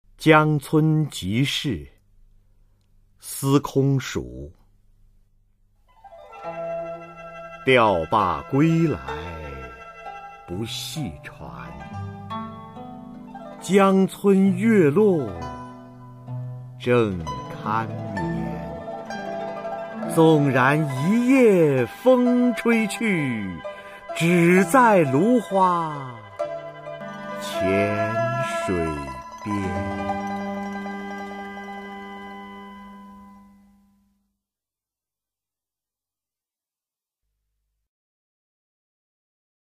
[隋唐诗词诵读]司空曙-江村即事 配乐诗朗诵